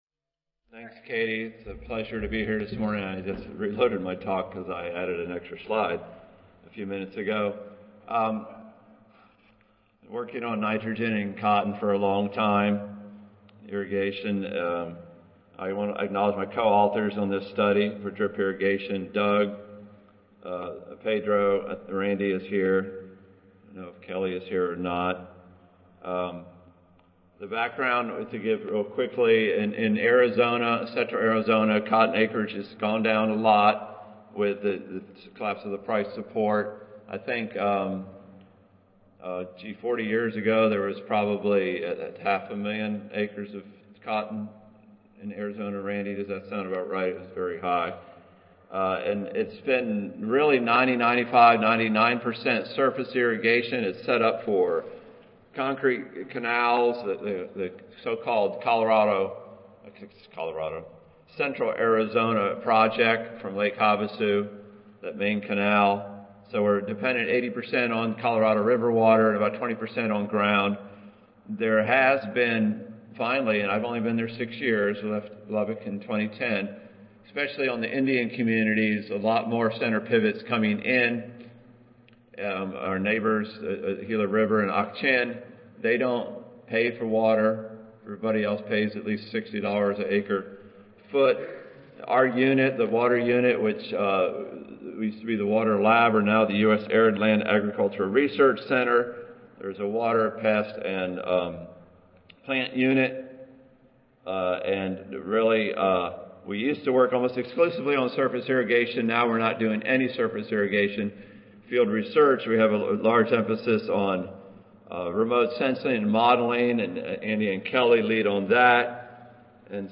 Recorded Presentation Following water, N fertilizer is the main constraint to cotton production in the western USA.